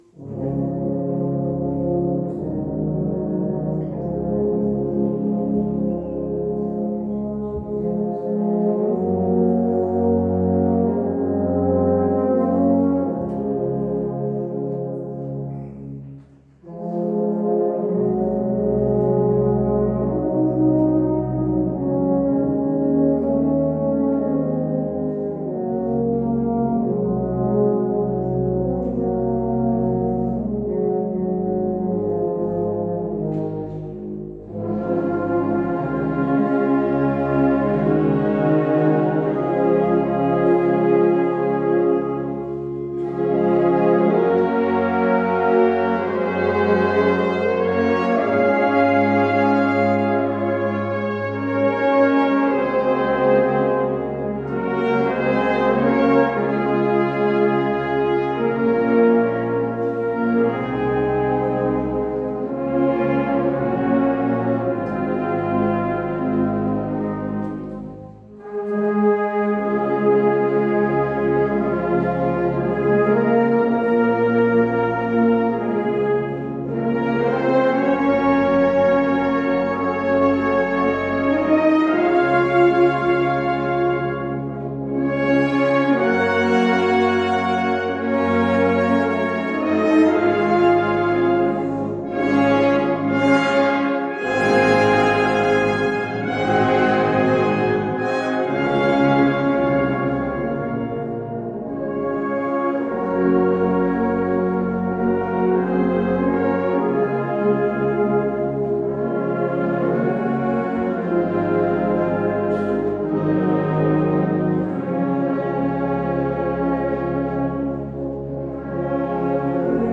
Mit dem Requiem von Julius Fucik erfolgte am Allerseelen-Tag 2019 ein äußerst würdiger Jahresabschluss.
Sanctus – Adagio religioso